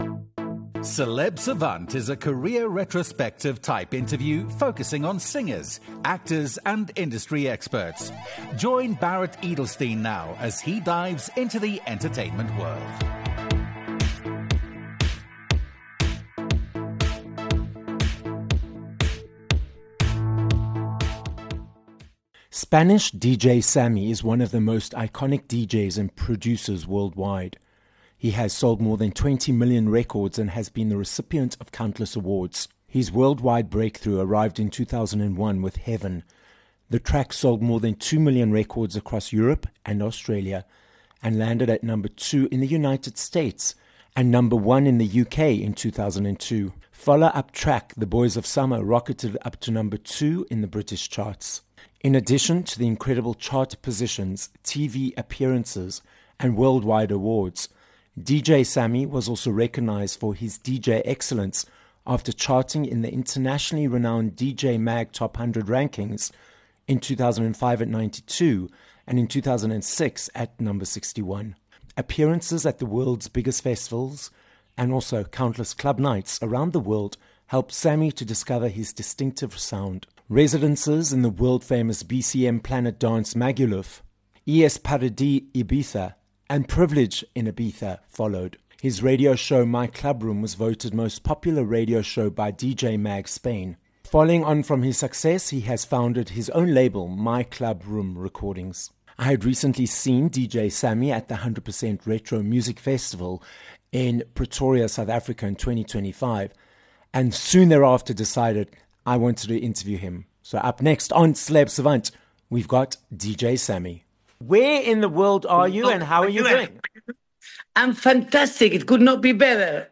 DJ Sammy - a Spanish DJ and producer who has sold more than 20 million records - joins us on this episode of Celeb Savant. We hear about Sammy's more than two decades in the business, how being passionate about art helped him succeed in the music industry, and what motivates him to keep creating music and performing.